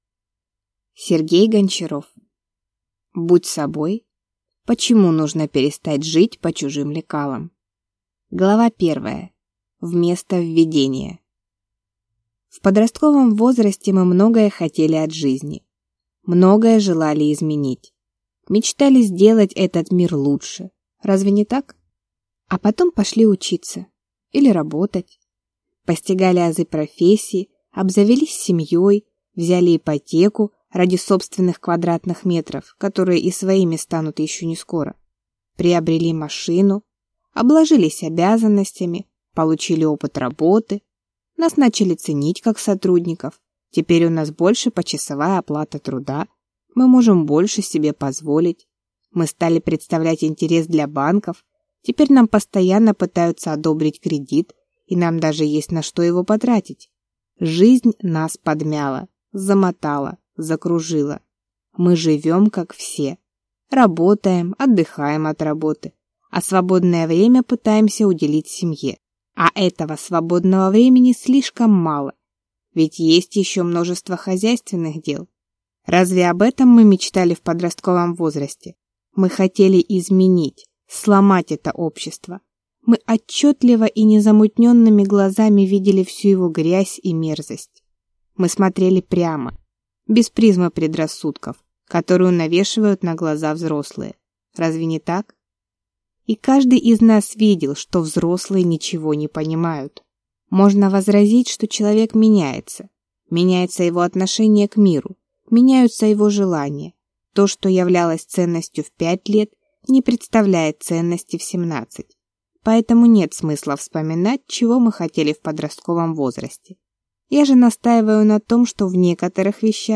Аудиокнига Будь собой! Почему нужно перестать жить по чужим лекалам | Библиотека аудиокниг